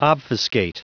Prononciation du mot obfuscate en anglais (fichier audio)
Prononciation du mot : obfuscate